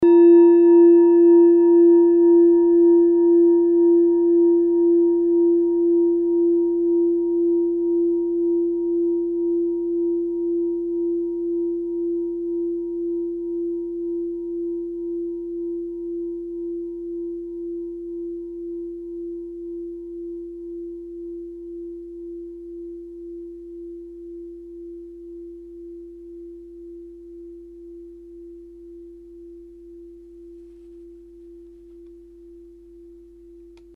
Klangschale Nepal Nr.1
Klangschale-Gewicht: 1080g
Klangschale-Durchmesser: 15,1cm
(Ermittelt mit dem Filzklöppel oder Gummikernschlegel)
klangschale-nepal-1.mp3